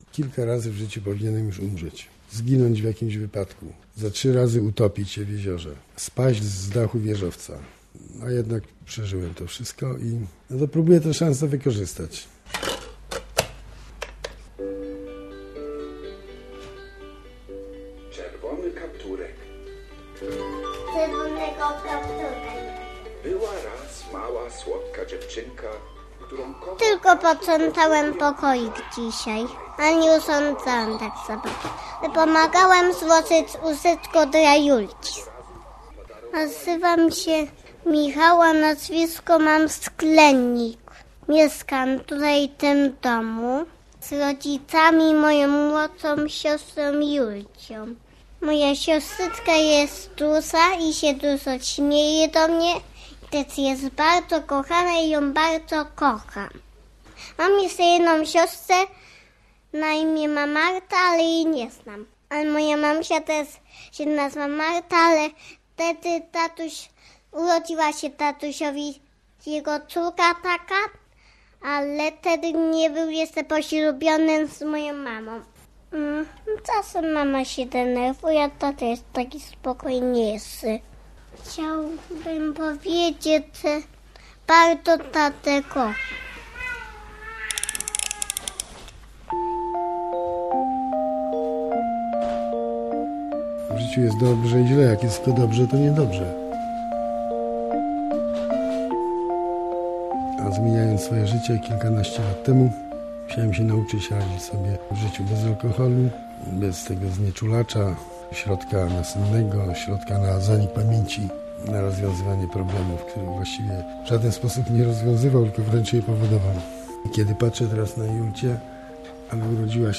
Pić już umiem - reportaż